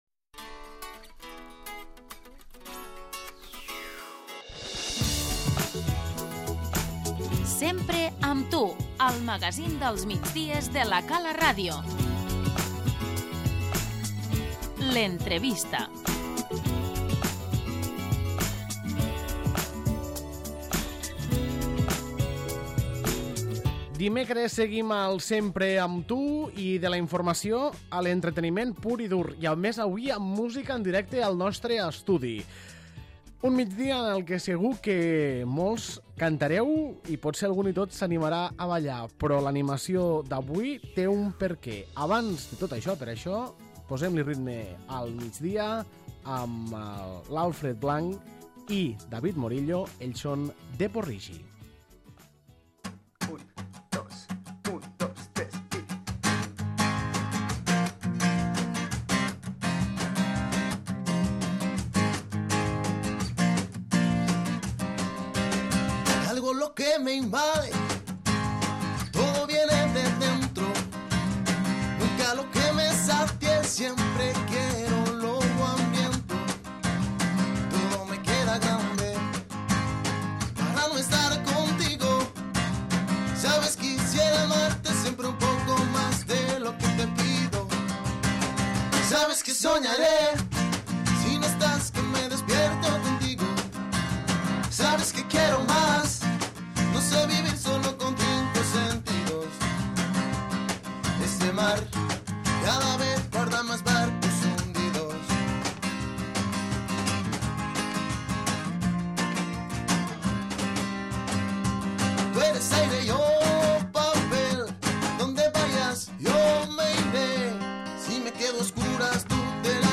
L'entrevista
Avui ens acompanyen amb la guitarra i el caixó, per oferir-nos un tastet del repertori que podrem escoltar dissabte a la Festa Salsera de la platja de l'Alguer.